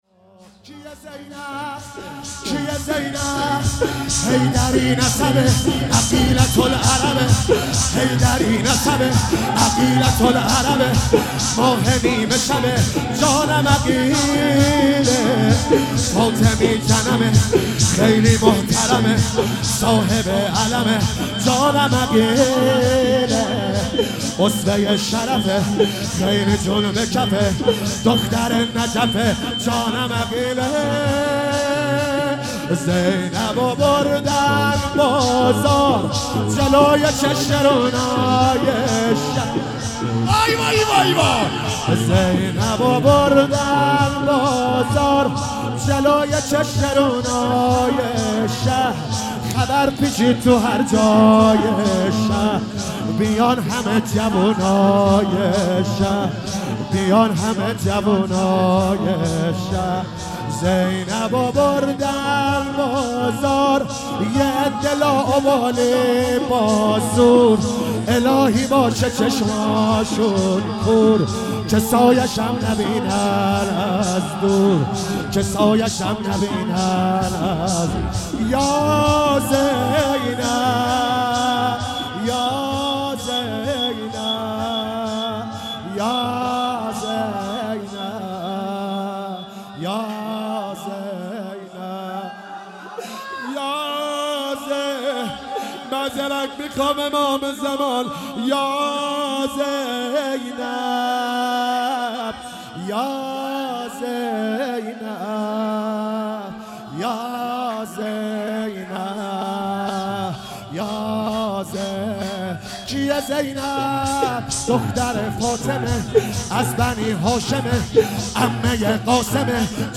شهادت امام باقر علیه السلام